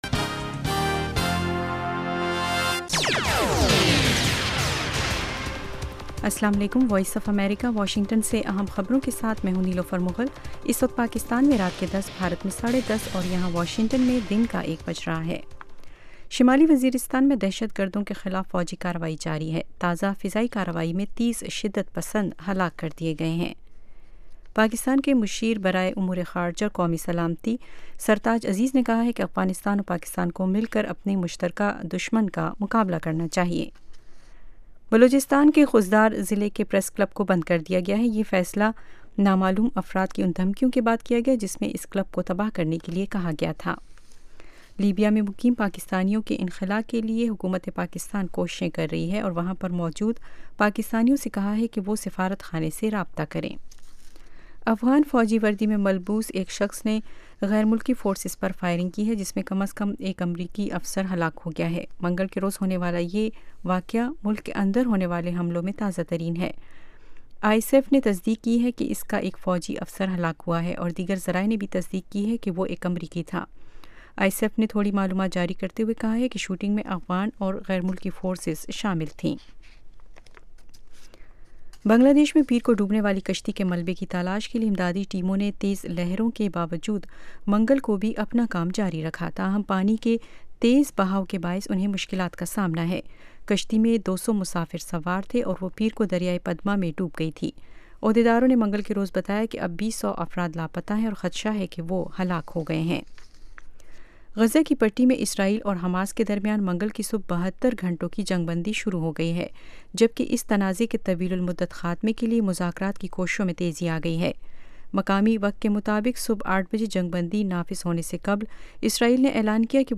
اس پروگرام میں تجزیہ کار اور ماہرین سیاسی، معاشی، سماجی، ثقافتی، ادبی اور دوسرے موضوعات پر تفصیل سے روشنی ڈالتے ہیں۔